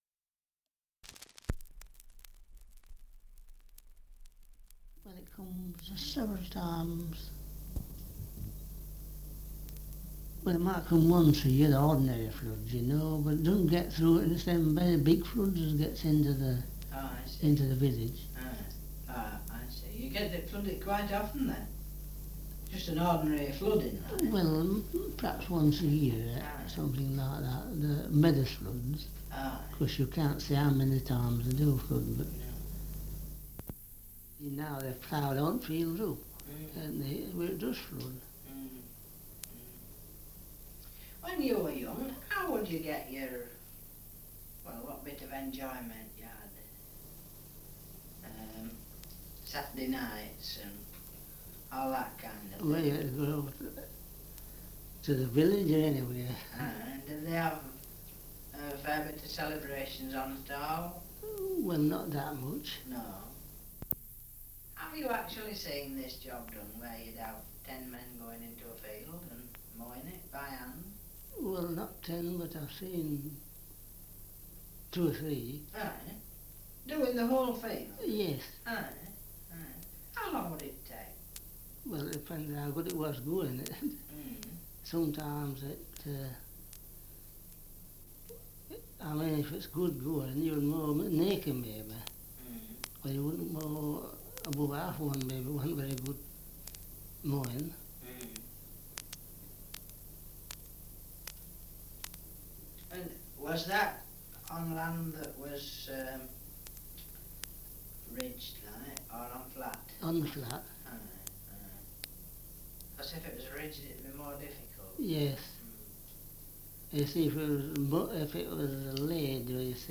2 - Survey of English Dialects recording in South Clifton, Nottinghamshire
78 r.p.m., cellulose nitrate on aluminium